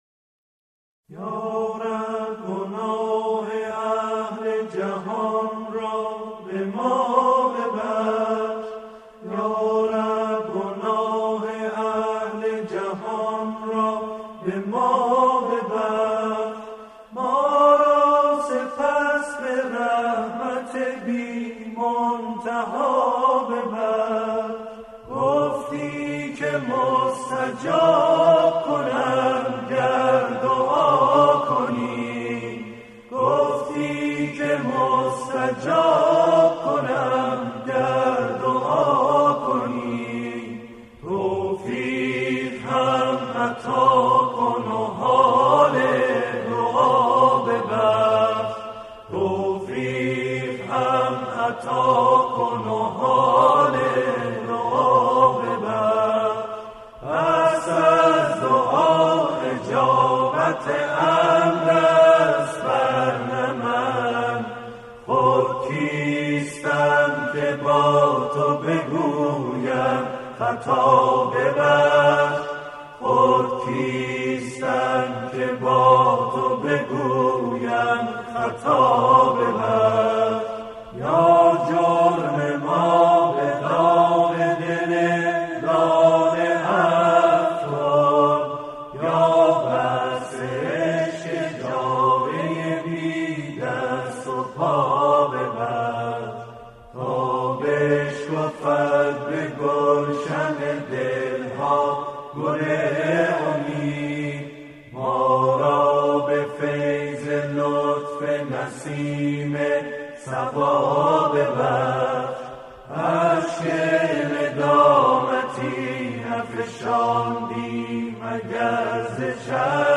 آکاپلا
توسط گروهی از جمعخوانان